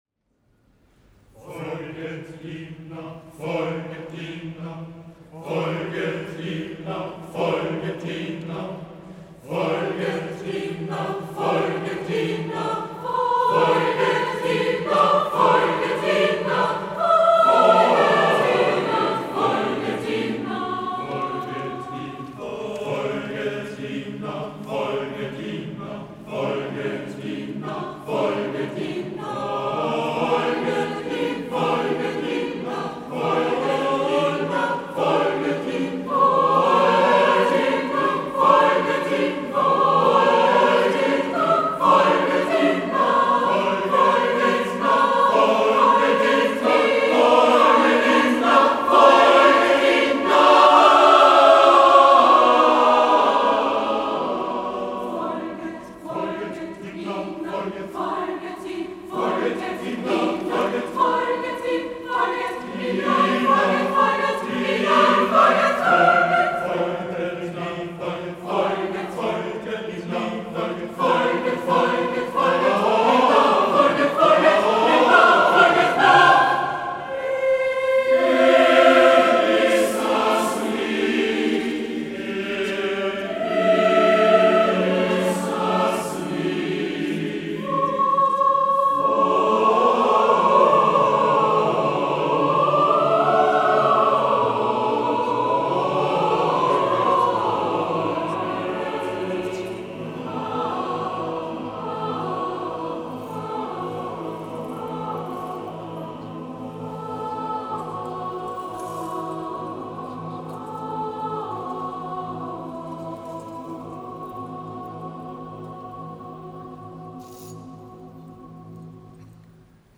CHORSTÜCK - Download Service
Die kontrapunktische Verarbeitung des Textes im Sinne einer apellhaften musikalischen Rhetorik versucht diesen thematischen Aspekt christlicher Nachfolge darzustellen.